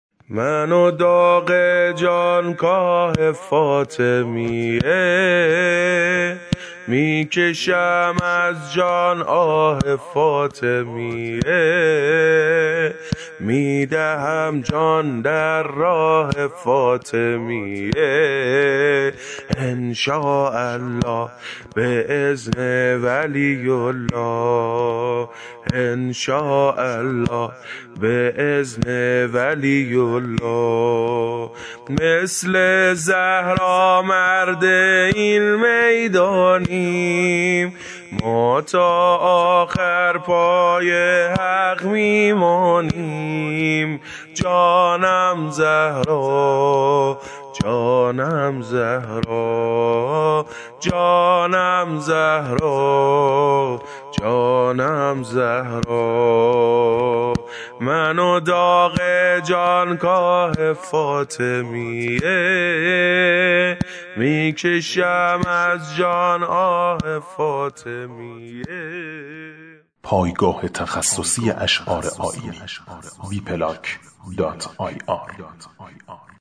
نوحه